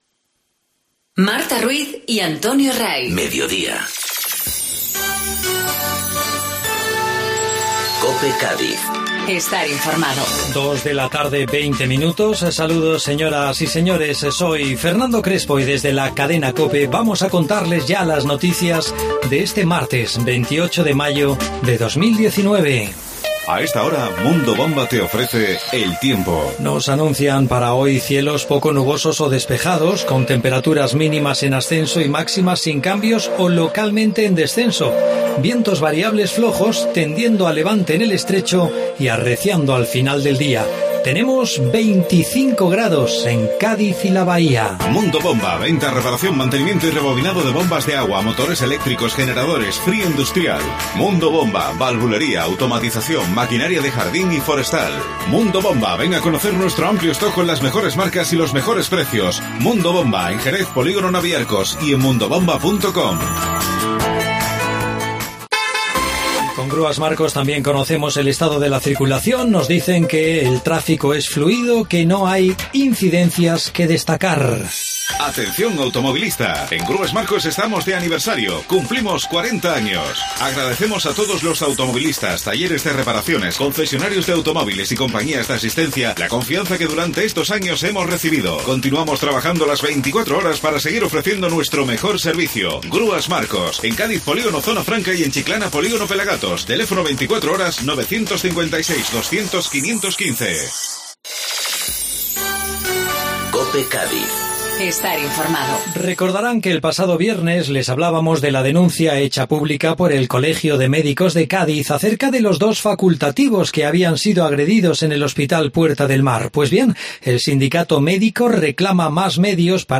Informativo Mediodía COPE Cádiz